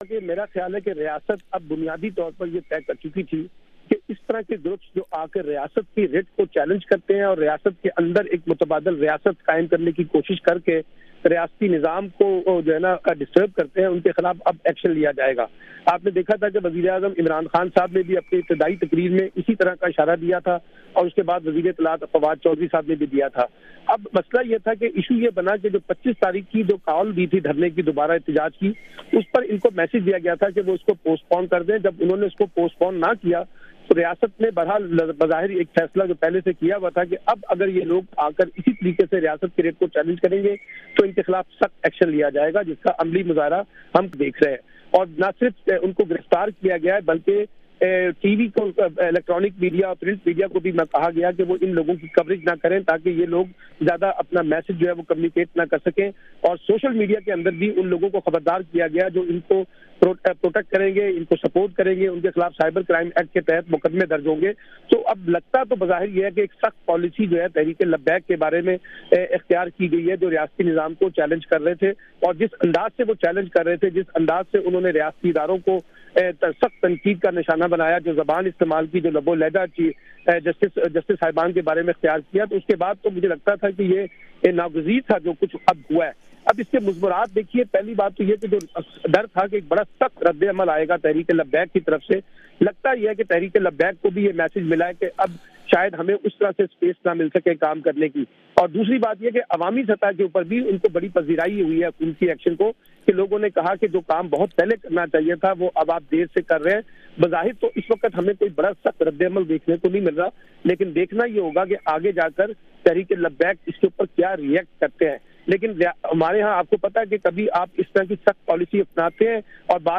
دو تجزیہ کاروں
تجزیہ کاروں کے رائے